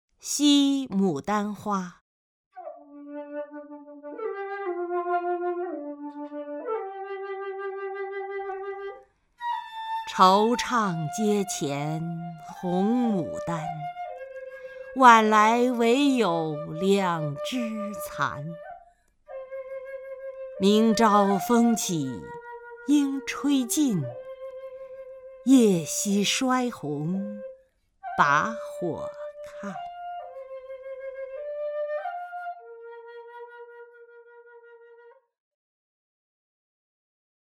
曹雷朗诵：《惜牡丹花》(（唐）白居易) （唐）白居易 名家朗诵欣赏曹雷 语文PLUS